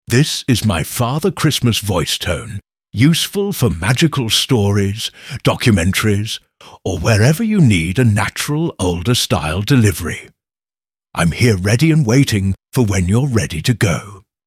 Male
English (British), English (Neutral - Mid Trans Atlantic)